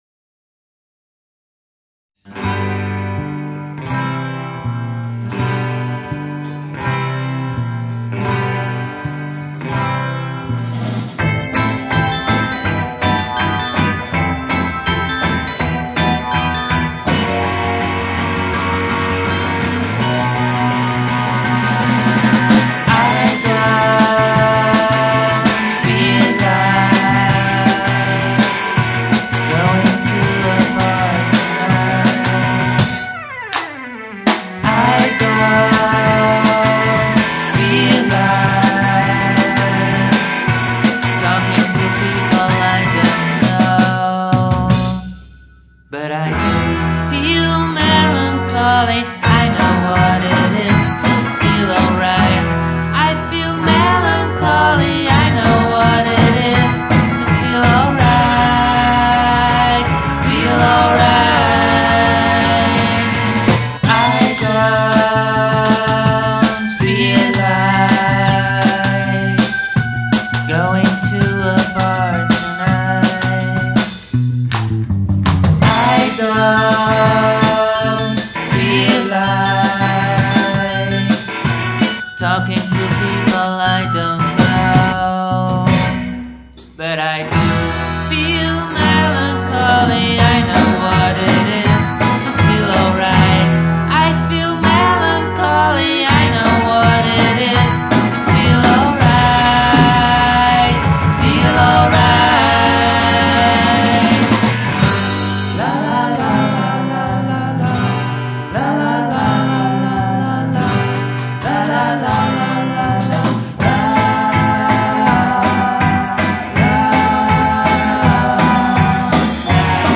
where : Studio Aluna , Amsterdam